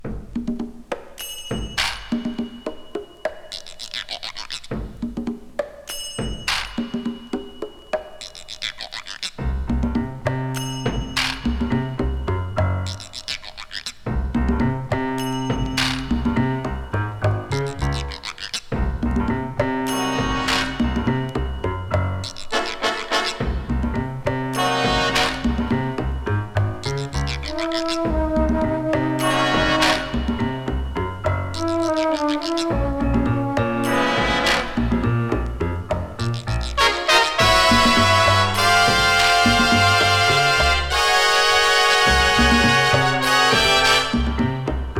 Jazz, Easy Listening, Lounge　USA　12inchレコード　33rpm　Stereo